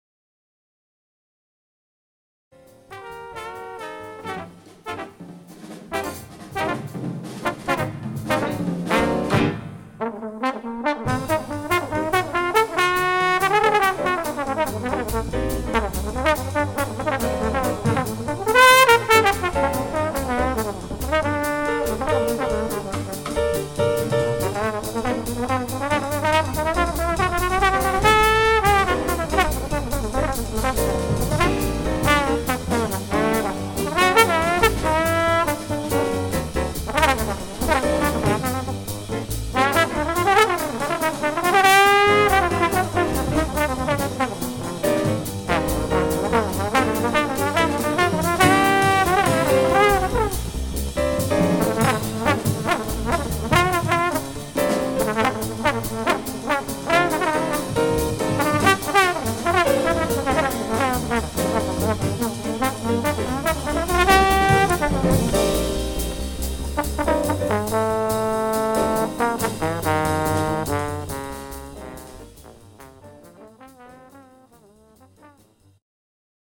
The Best In British Jazz
Recorded at Norden Farm Centre for the Arts, Jan 9th 2014